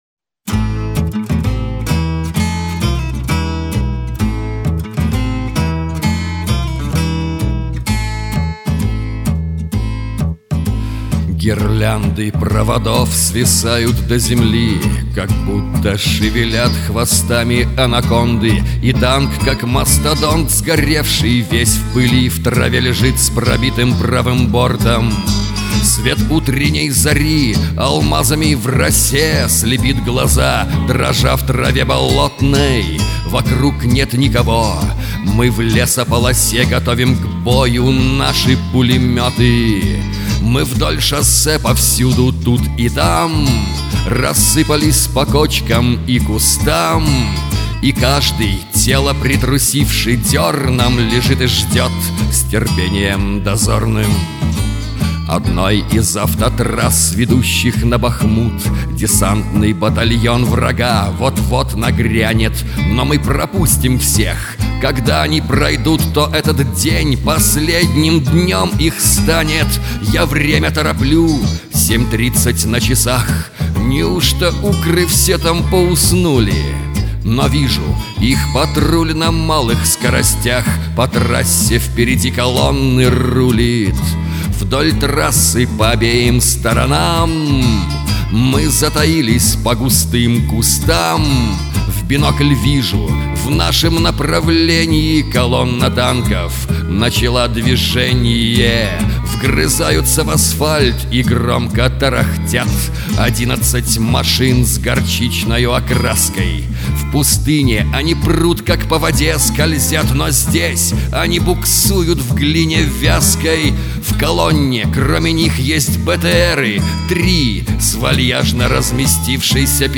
Зарисовка одного боя на мотив песни В. Высоцкого «Горизонт».